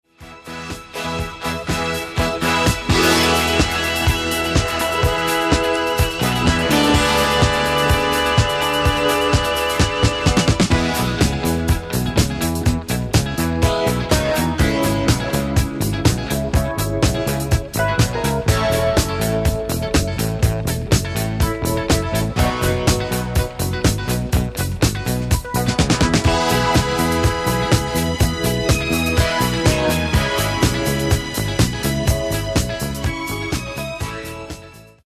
Genere:   Philly Sound | Soul